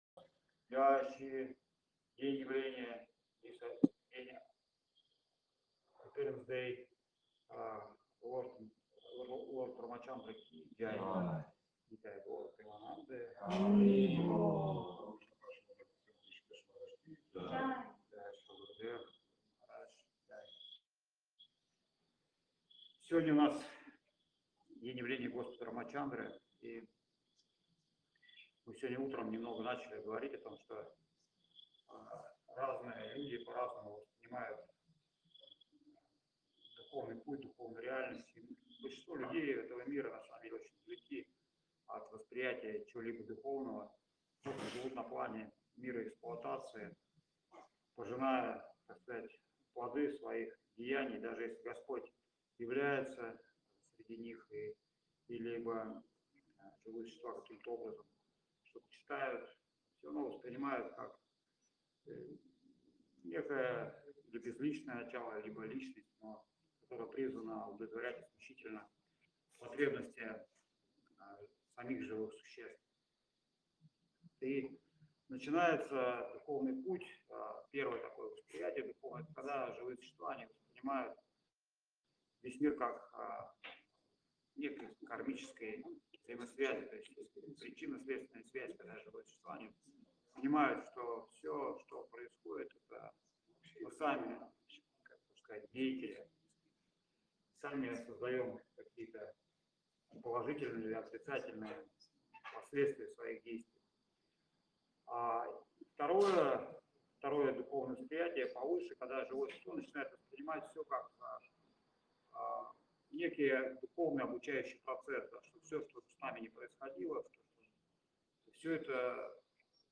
Лекции полностью